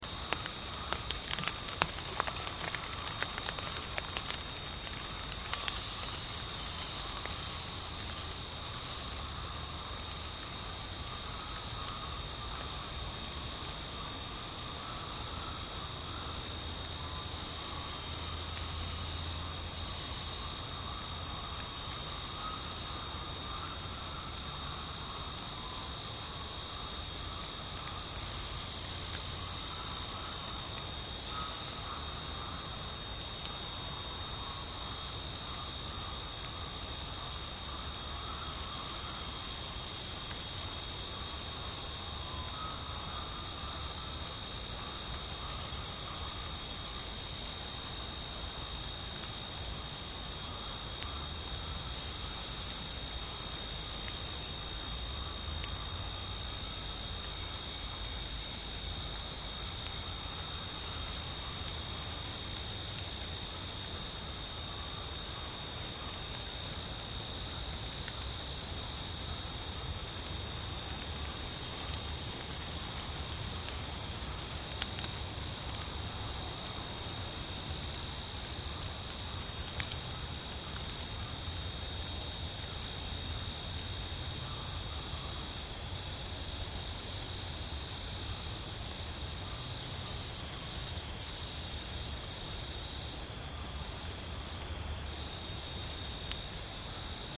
Powerful or Masked Owl?
Mystery nocturnal found on SERF Sensor at 21.18hrs on 15 October 2010
This was the only call of interest between 8pm and midnight, as it rained throughout the period, frogs and insects are calling in the background.
Masked and Sooty Owls have similar cicada-like calls but are a bit quite different to my ear.
Mystery nocturnal call (tt98?)